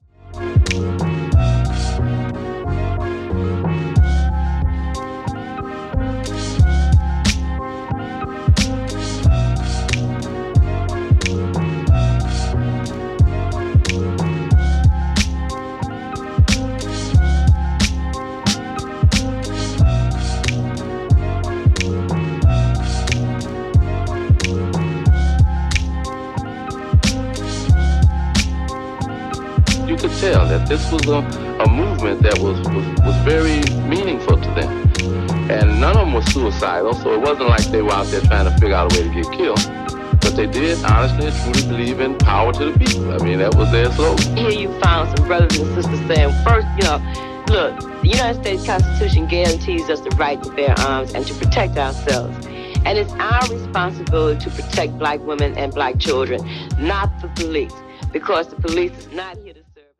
panther trim.mp3